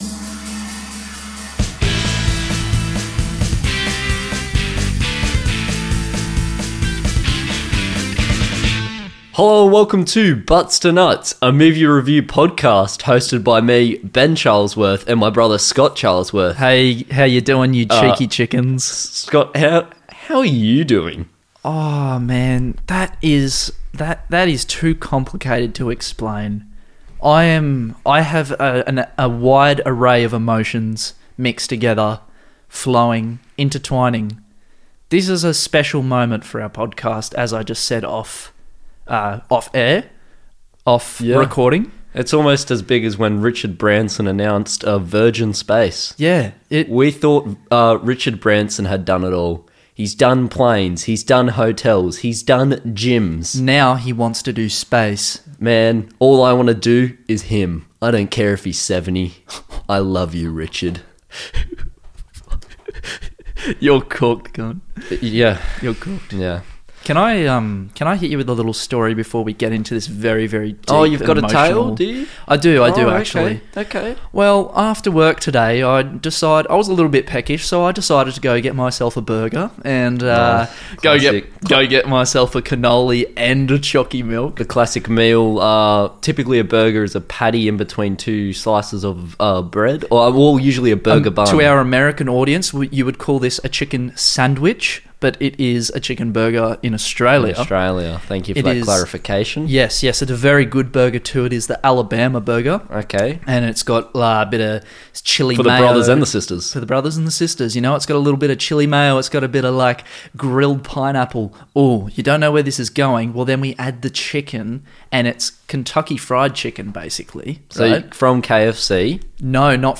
This week the boys reviewed Toy Story! This episode includes space rangers, Randy Newman and a freestyle rap.